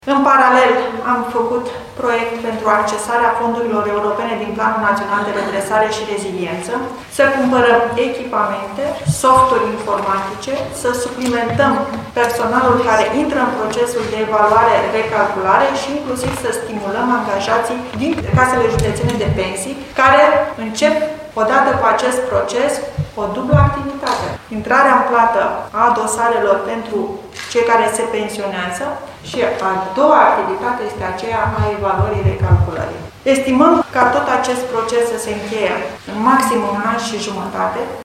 La final, în cadrul unei conferințe de presă, ea a declarat că toate pensiile vor fi evaluate și recalculate în format electronic.